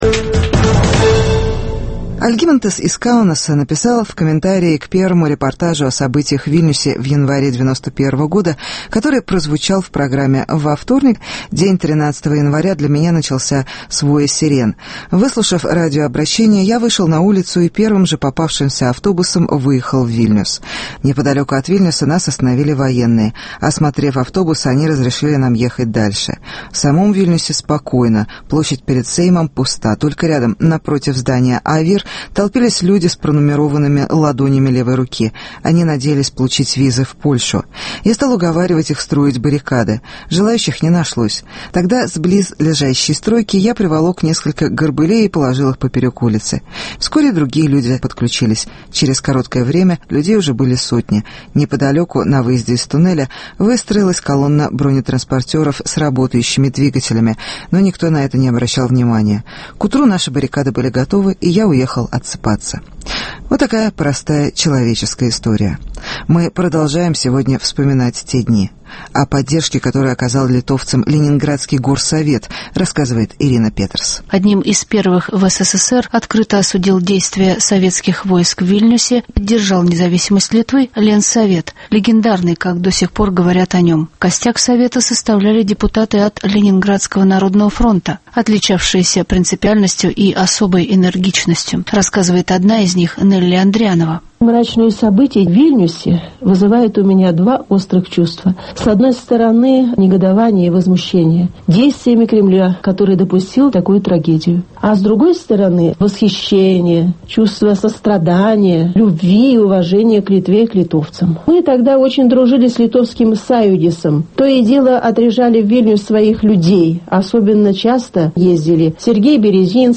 Продолжение серии репортажей о событиях в Вильнюсе в январе 1991 года